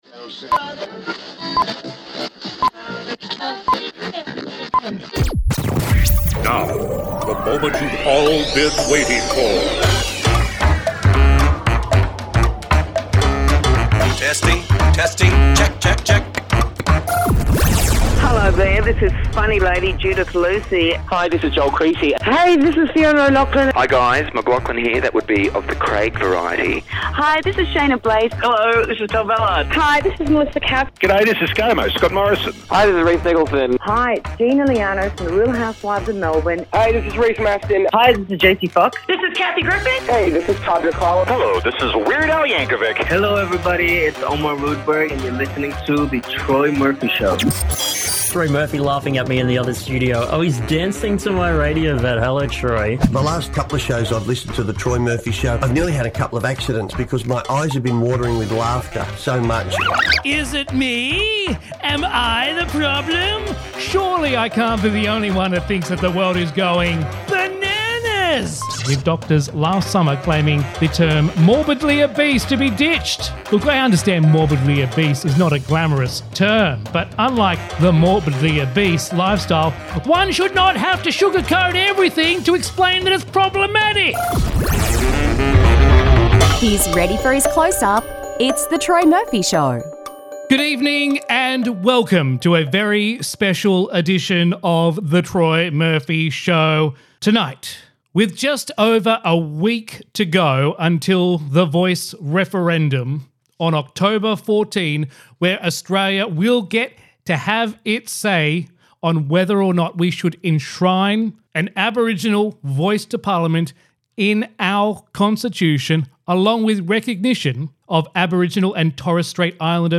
interview-yes-23-sutherland-shire-on-the-voice-referendum.mp3